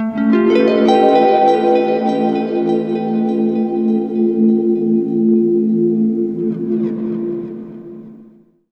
GUITARFX16-L.wav